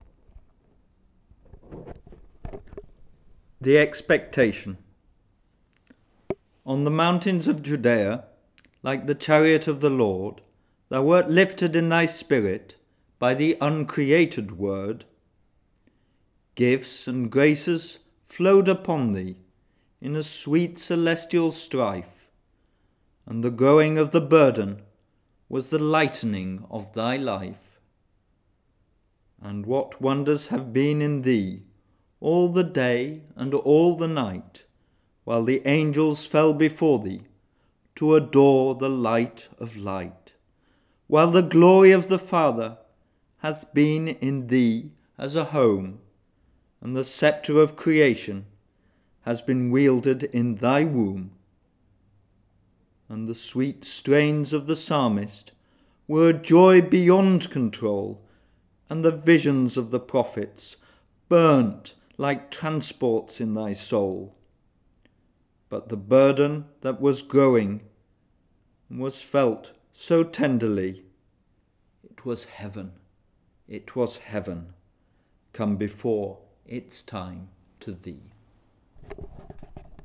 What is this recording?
Tags: poetry speeches